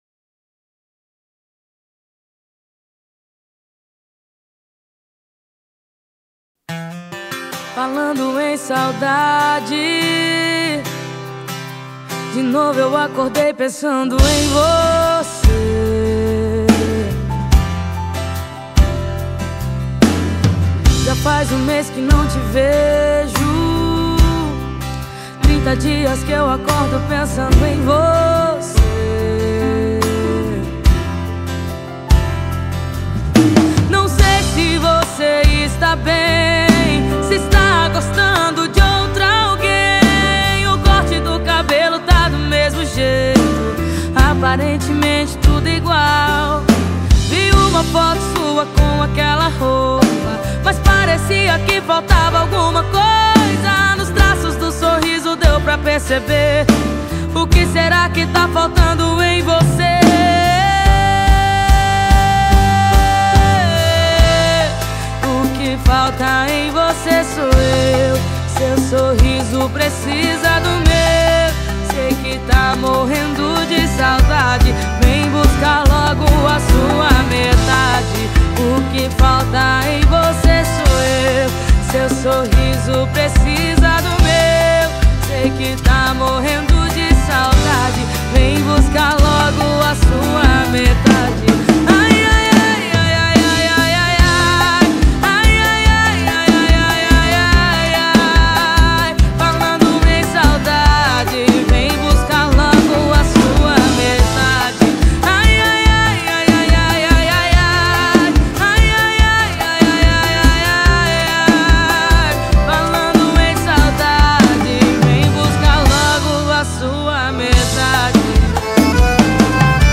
2025-01-31 21:06:51 Gênero: Sertanejo Views